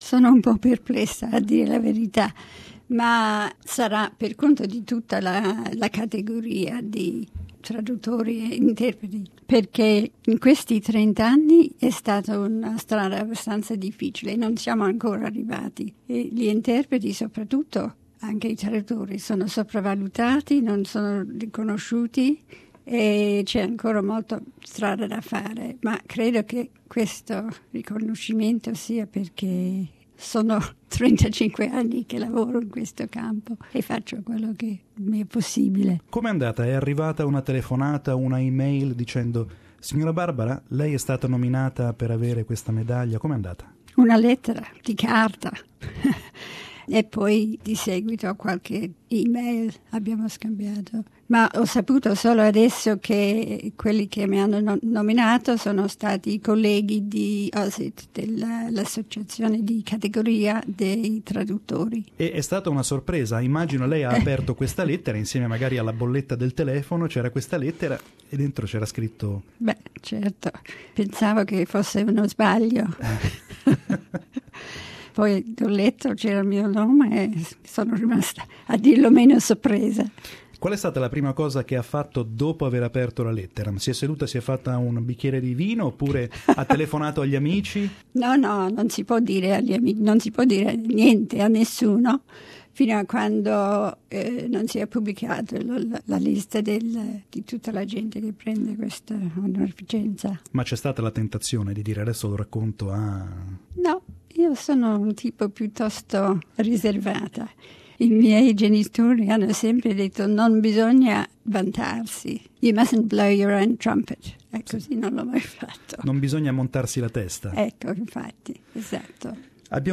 abbiamo ospitato nei nostri studi di Sydney